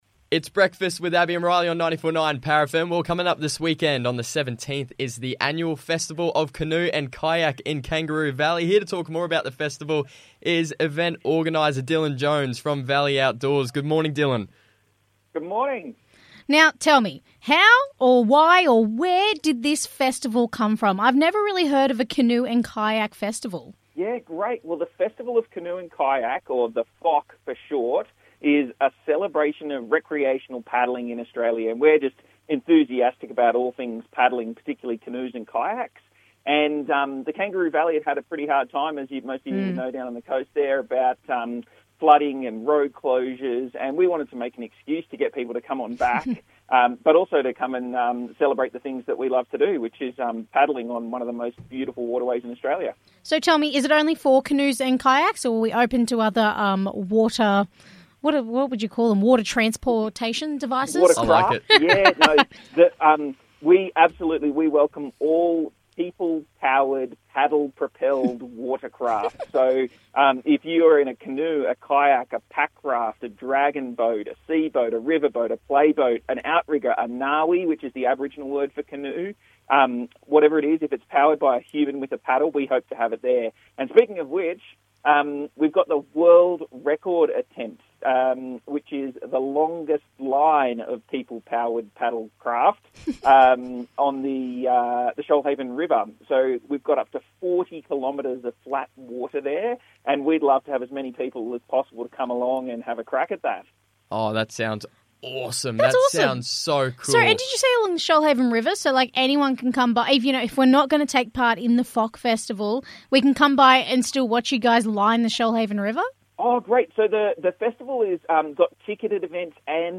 joined the breakfast show to talk more about the festival and what to expect.